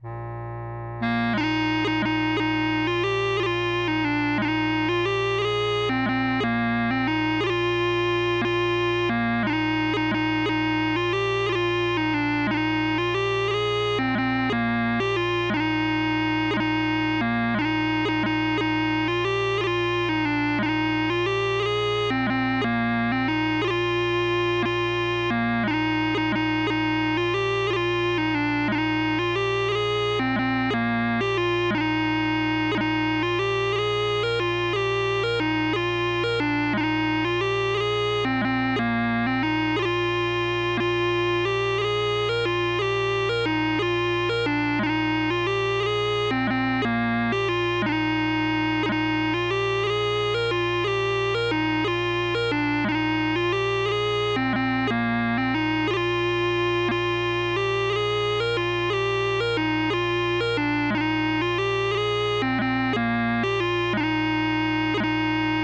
Bagpipes and Drums
PIPES: BWW/ PDF /